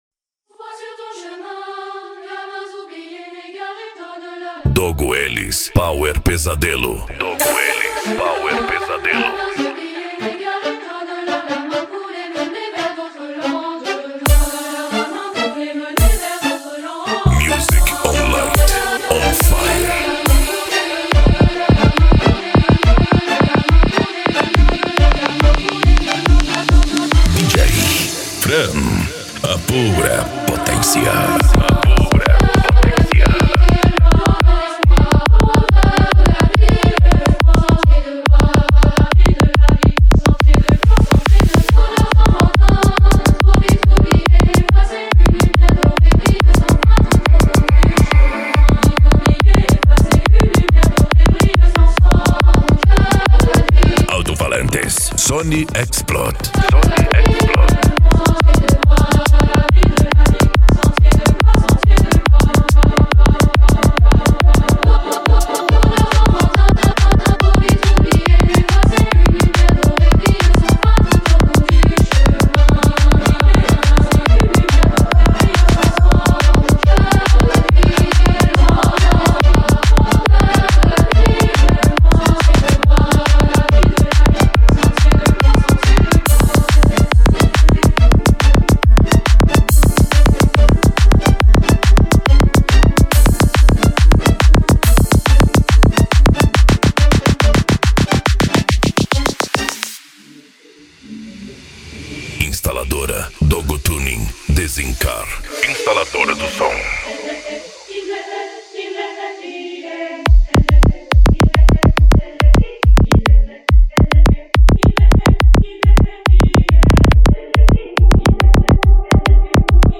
Musica Electronica
Psy Trance
Remix
Techno Music
Trance Music